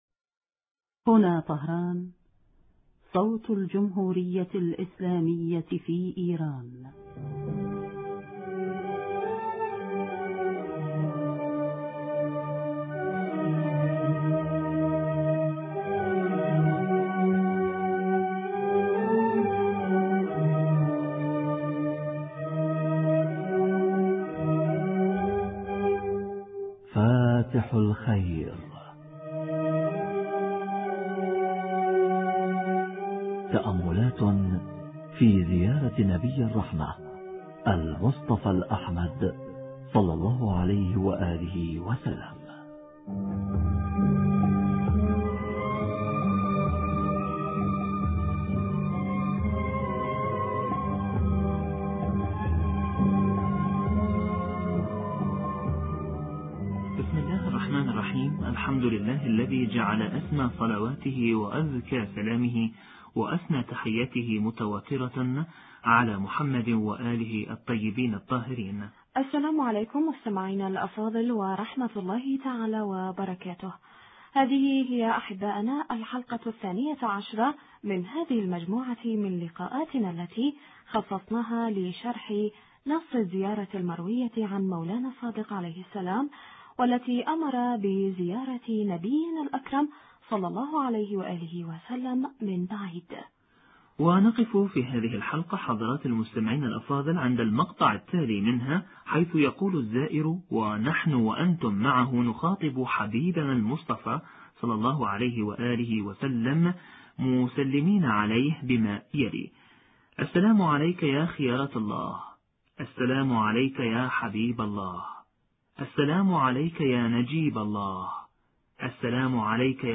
شرح معنى كون النبي الاكرم(ص) (خيرة الله وحبيبه ونجيبه) حوار